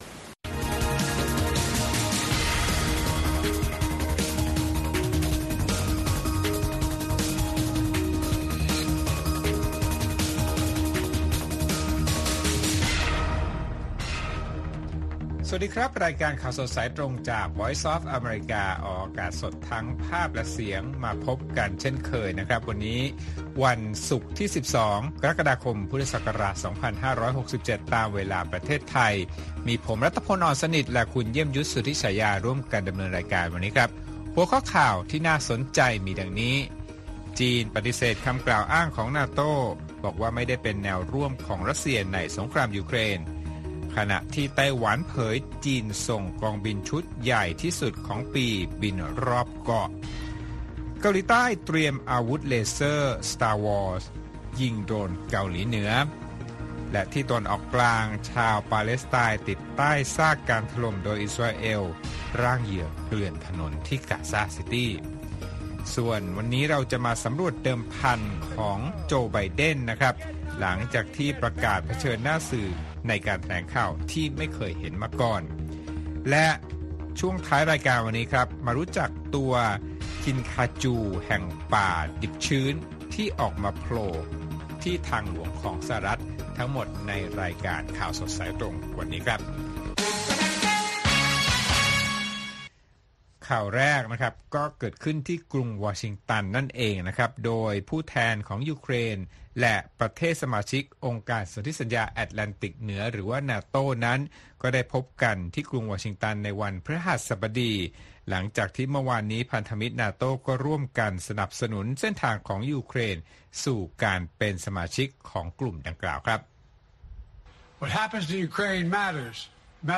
ข่าวสดสายตรงจากวีโอเอ ไทย วันศุกร์ ที่ 12 กรกฎาคม 2567